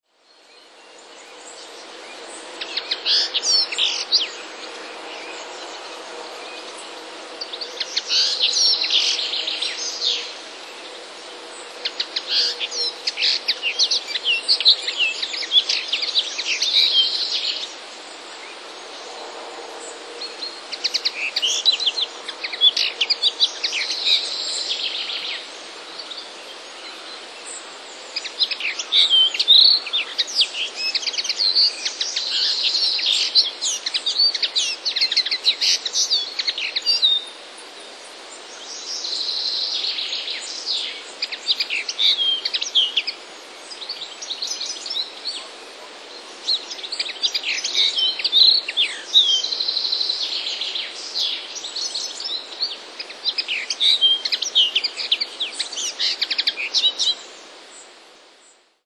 Quizy "Poznaj ptaka po śpiewie"
Przed każdym formularzem umieszczony jest link do pliku z nagraniem śpiewu ptaka, który jest na pierwszym planie.